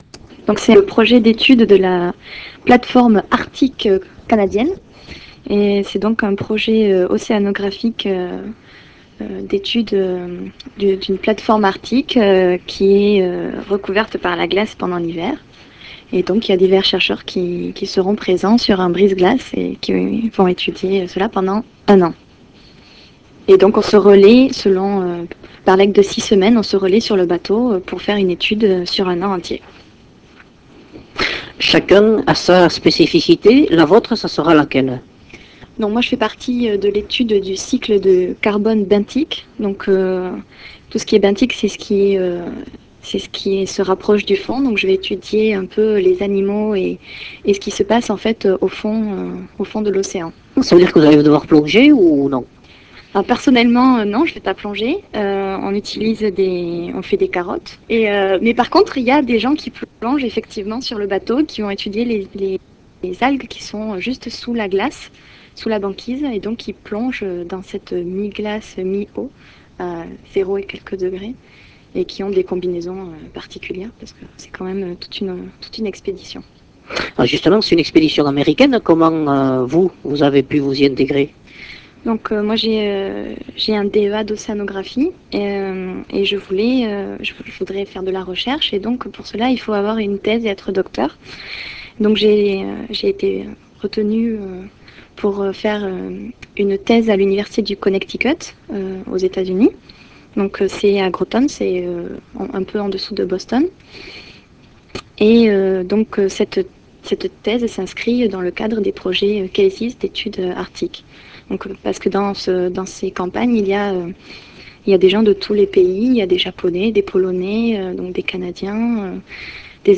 I had an interview with Radio Pais, a radio in "bearnais", the regional language of my area, where I spoke about my plan.